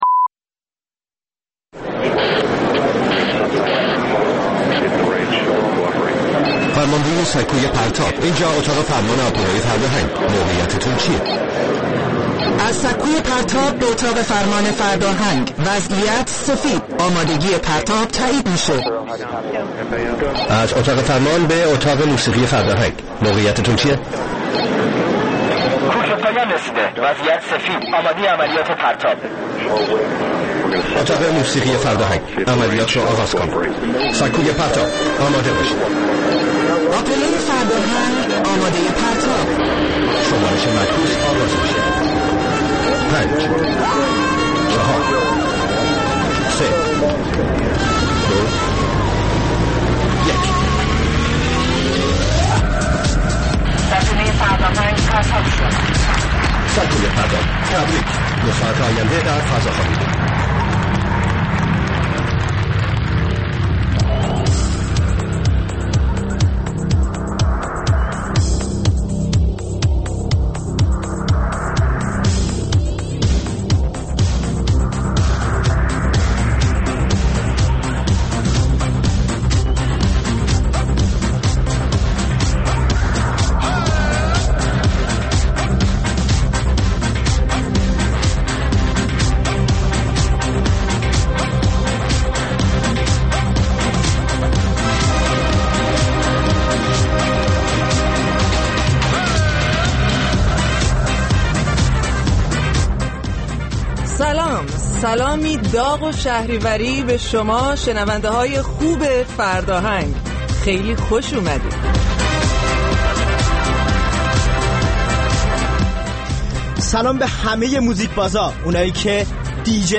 برنامه زنده موسیقی